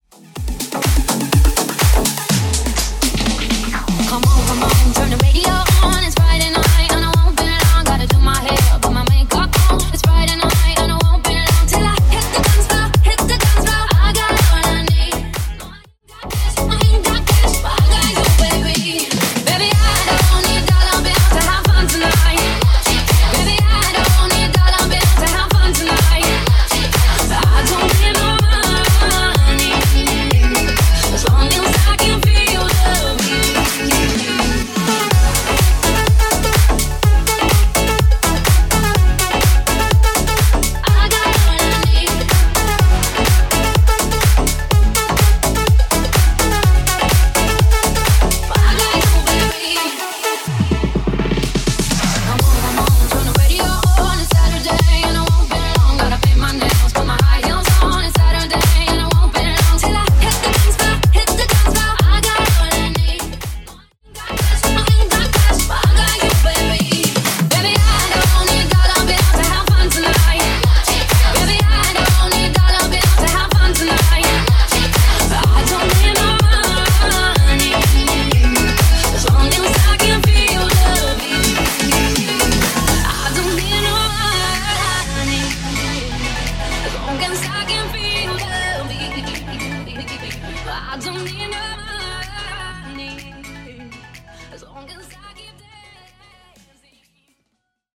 BPM: 125 Time